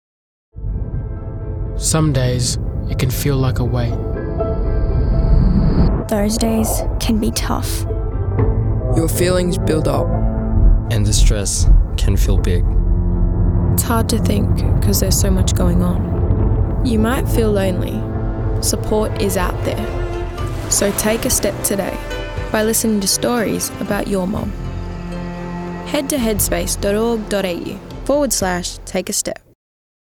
This public service announcement is meant to raise awareness and support for children and adolescents in need. The sound design is meant to invoke a feeling of a muffled and oppressive reality which transforms and resolves into a clear and rich sounding environment.